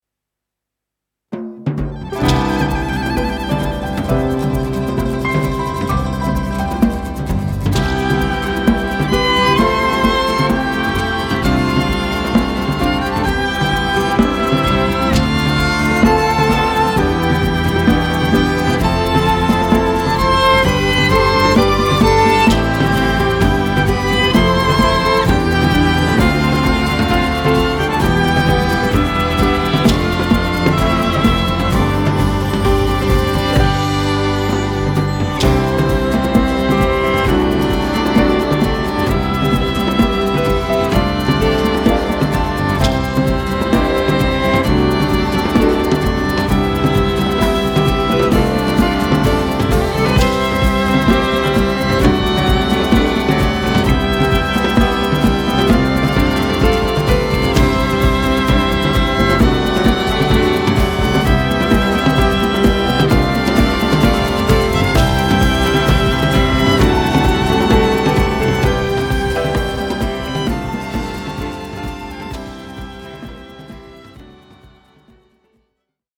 バイオリンやビオラを三声くらい重ねてもらったり、贅沢なサウンドにしてもらいました。
トロンボーンの音が入るだけで、なんとも温かい異国の雰囲気が出ます。